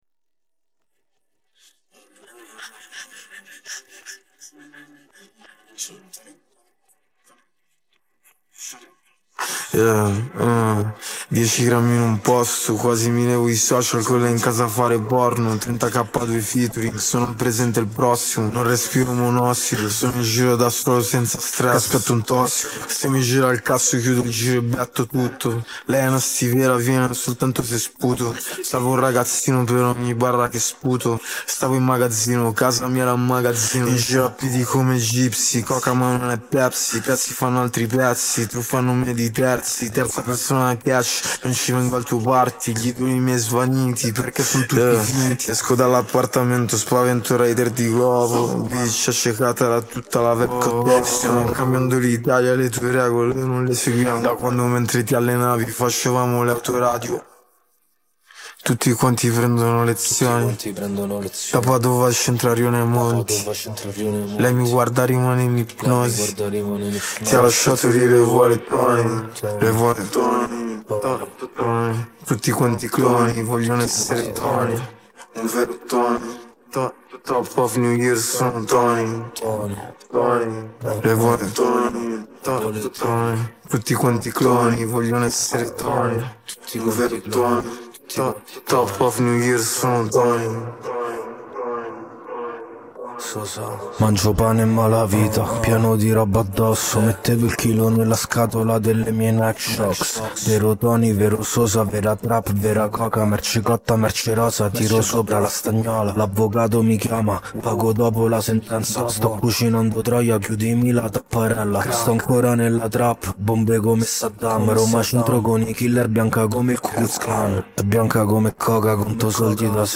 Parte vocale